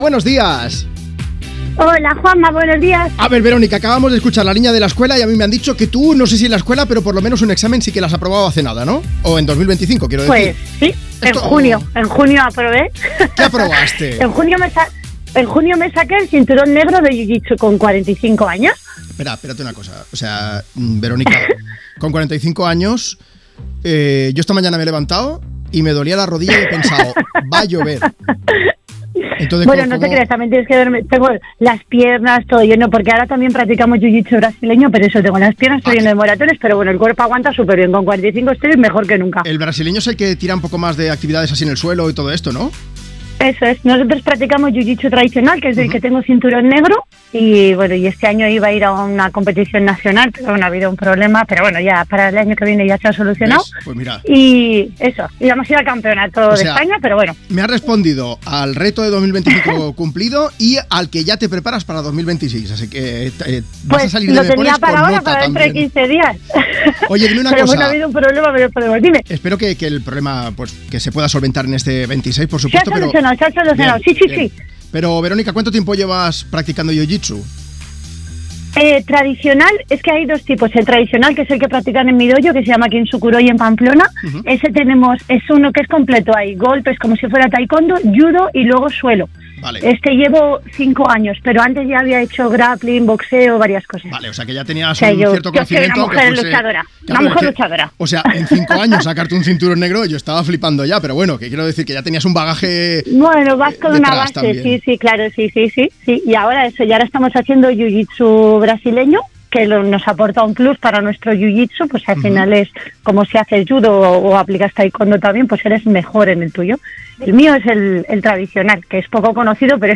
Una oyente de 'Me Pones' consigue el cinturón negro de 'jiu-jitsu' a los 45 años 4:13